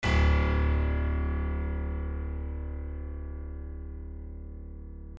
piano-sounds-dev
HardPiano